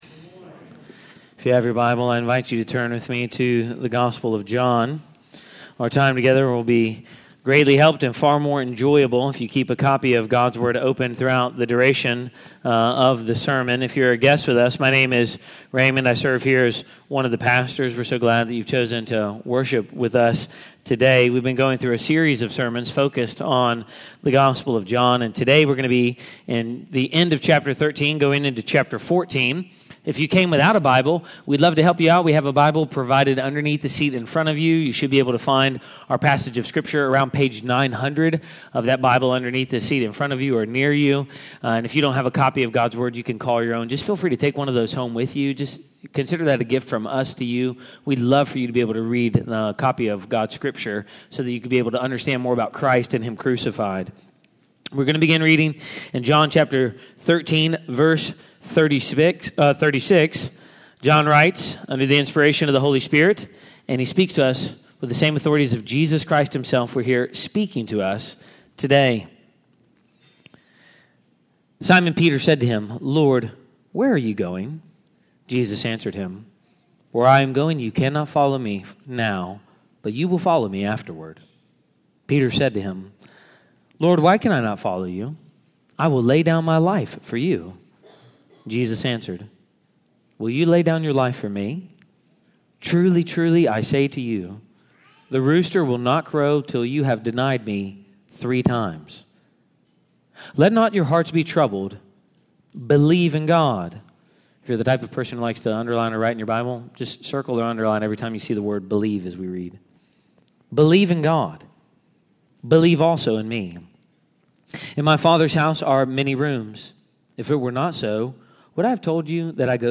SERMON-106.mp3